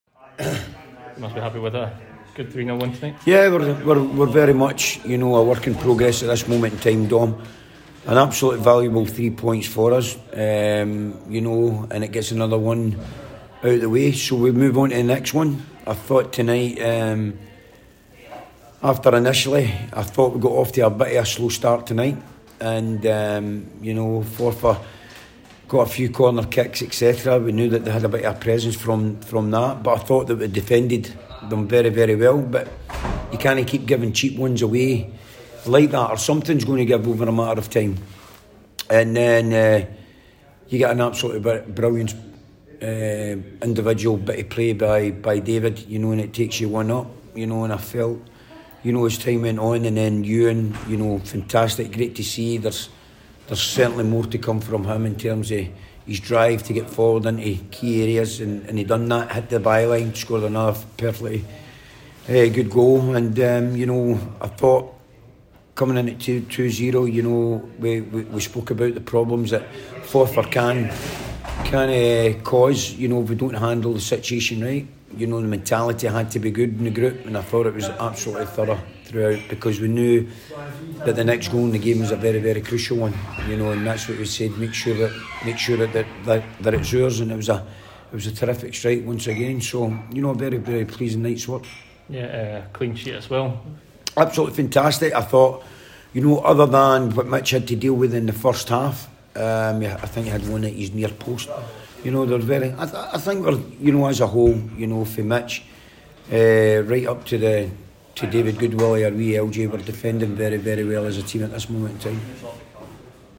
press conference after the League 1 match.